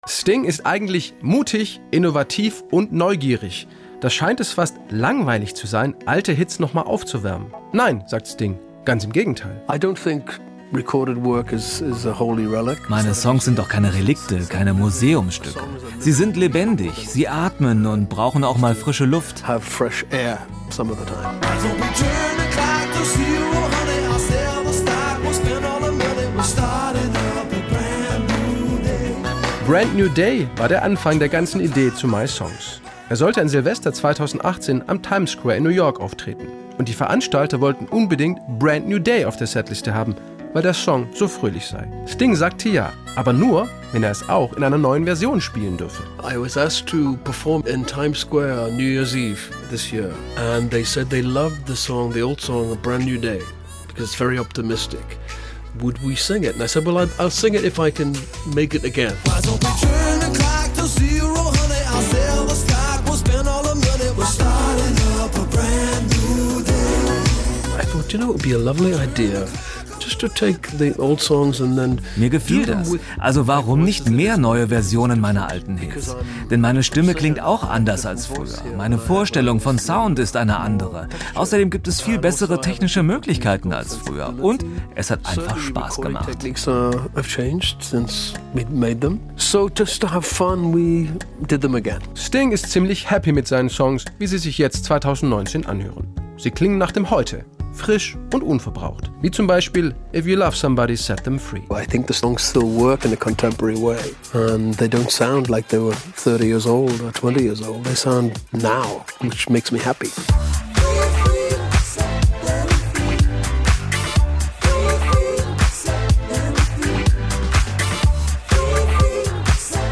Interview mit Sting Teil 1: Sting über das neue Album "My Songs"